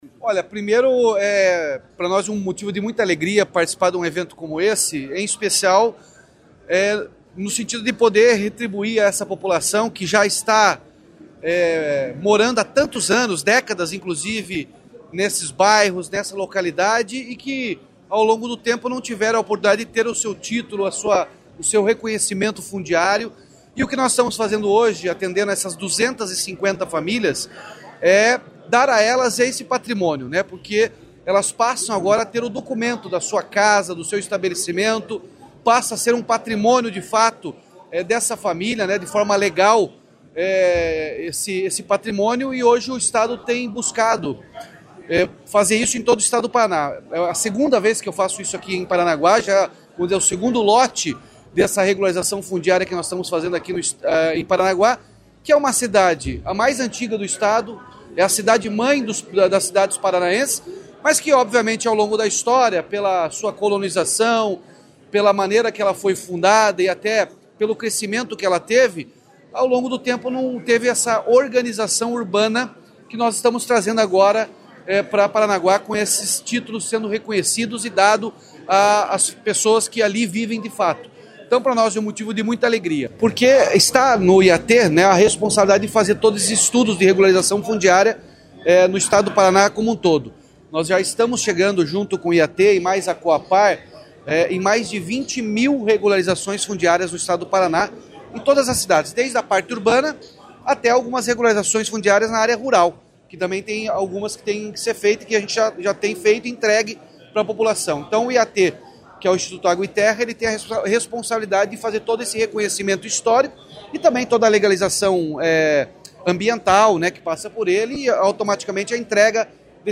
Sonora do governador Ratinho Junior sobre a entrega de títulos de propriedade de imóvel a 224 famílias do Litoral